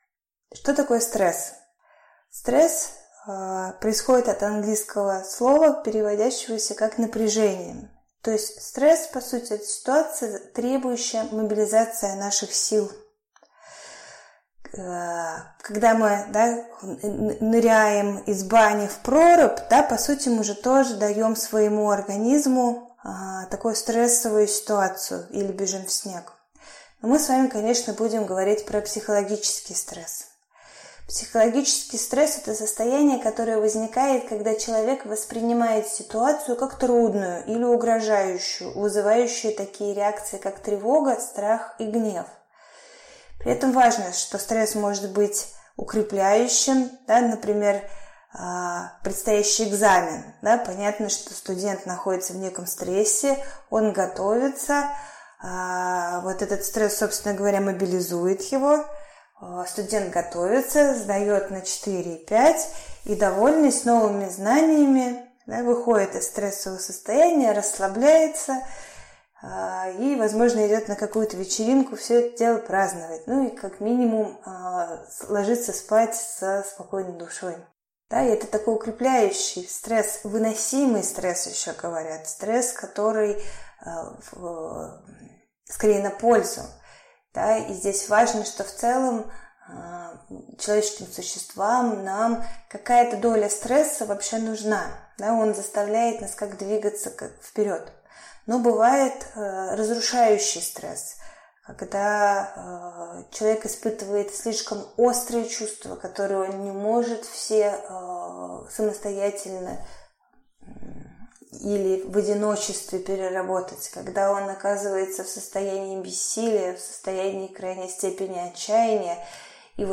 Аудиокнига Аптечка психологической самопомощи: Стресс | Библиотека аудиокниг